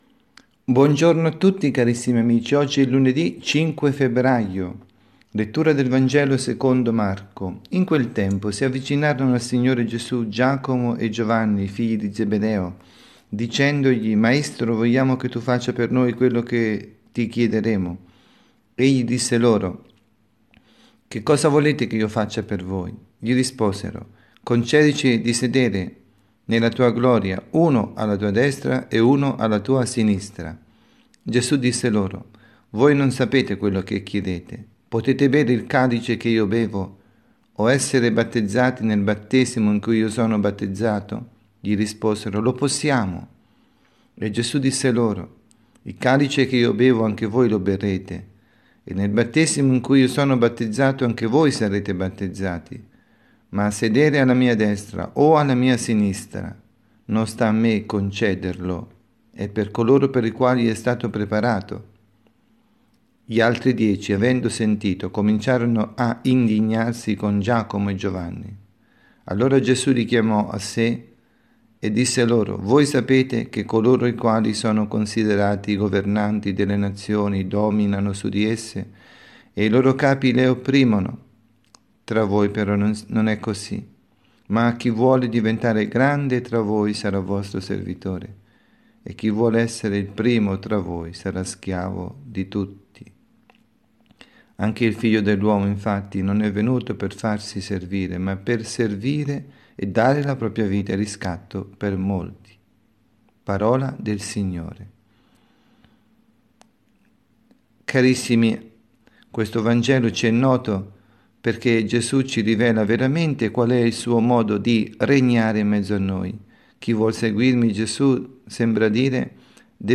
Catechesi
dalla Parrocchia S. Rita –  Milano